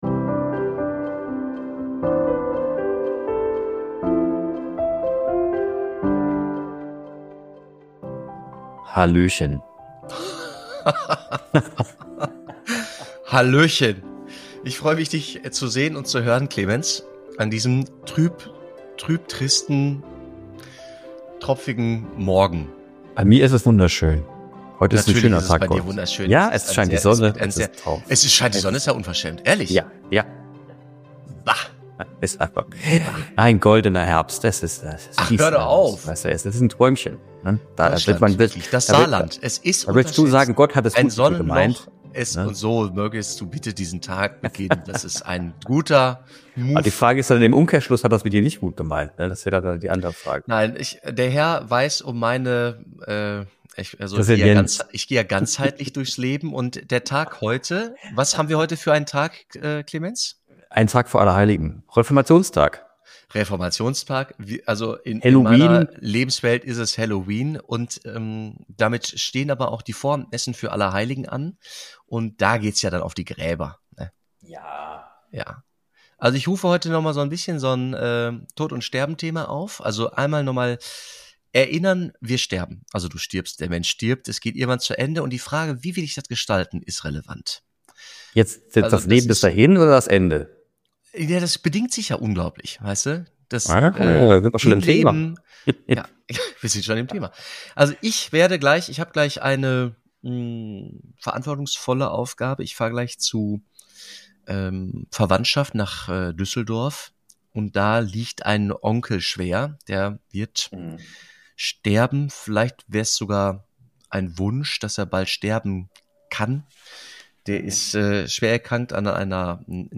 Und welche Folgen hat das für die, die zurückbleiben? Ein Gespräch über neue Bestattungsgesetze, den Wandel der Trauerkultur und die Verantwortung gegenüber den Angehörigen – mit Witz, Tiefgang und einem Schuss Sarkasmus.